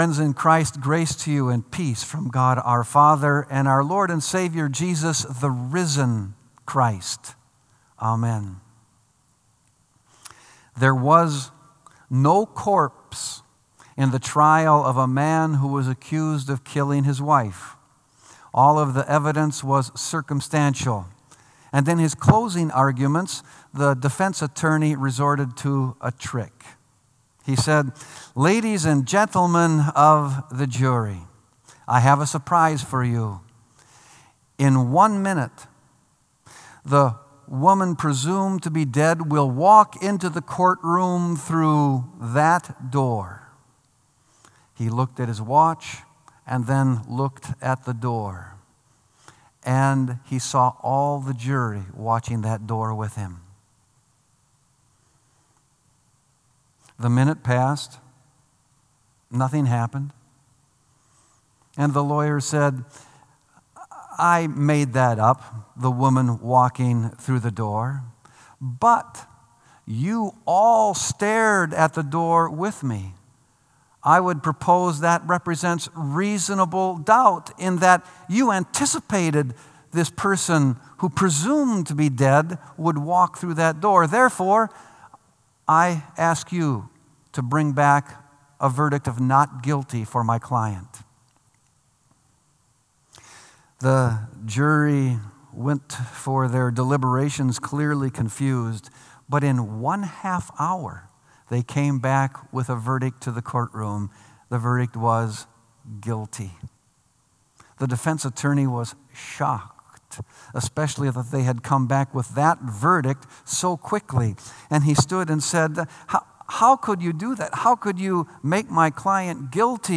Sermon “God Makes Clean”